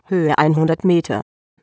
Dazu habe ich mir 100m - 200m - 300m... als Sprachansage bei AT&T erzeugt und die Alarme mit x>100m ... gesetzt.
Wenn du in dem AT&T Link die deutsche Damenstimme nimmst, klingt sie sehr ähnlich.